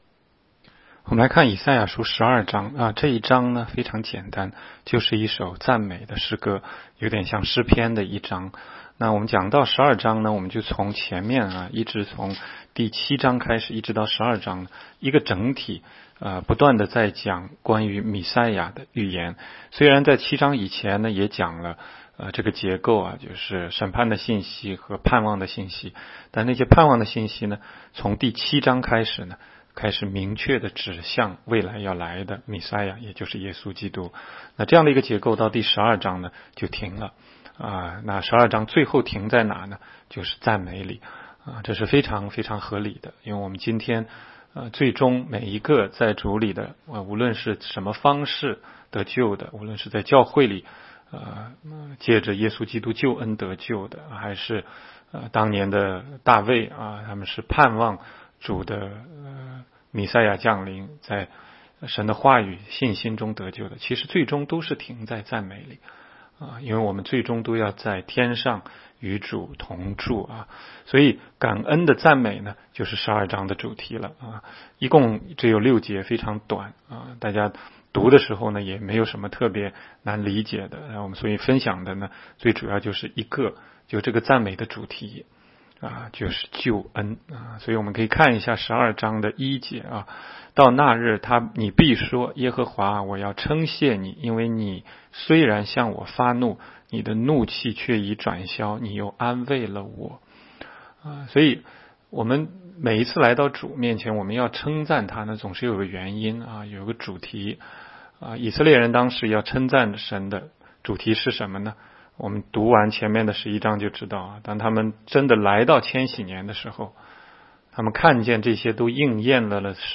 16街讲道录音 - 每日读经 -《 以赛亚书》12章